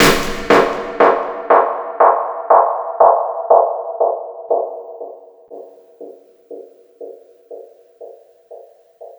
INSNAREFX2-L.wav